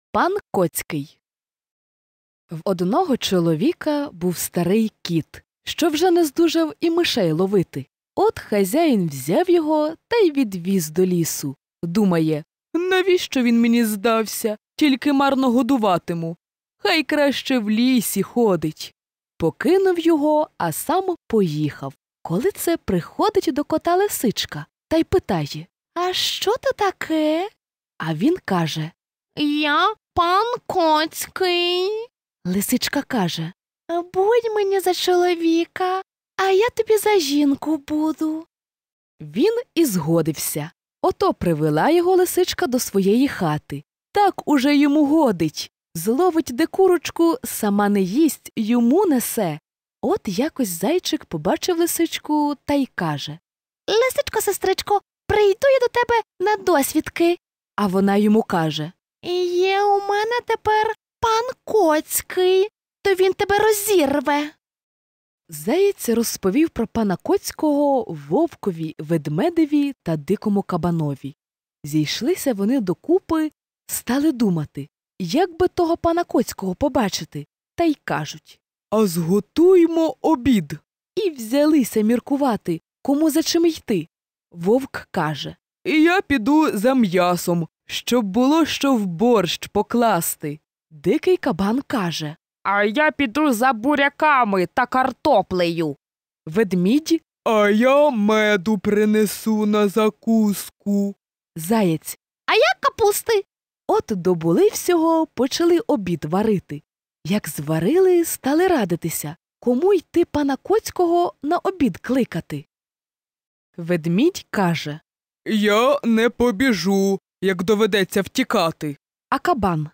Аудіоказка “Пан Коцький” українською – слухати та скачати безкоштовно в форматах MP3 і M4A
Аудіоказки для маленьких діточок: слухати і завантажити